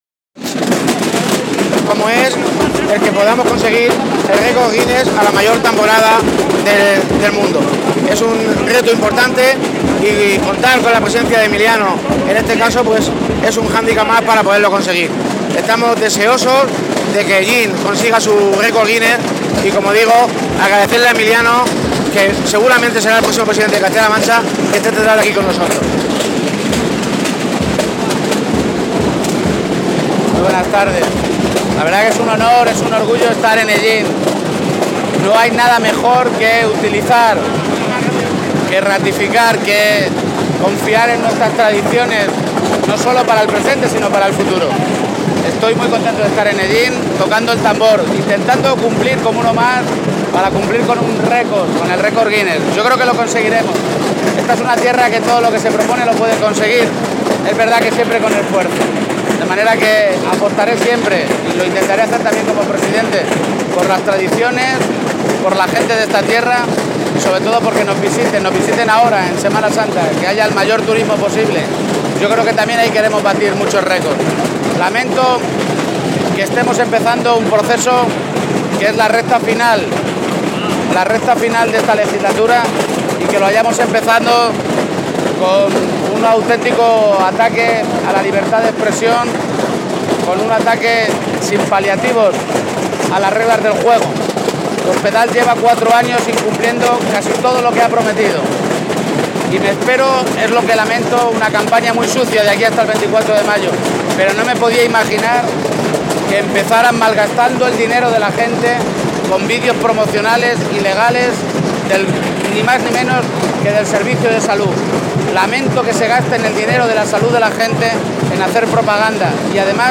García-Page atiende a los medios de comunicación en Hellín.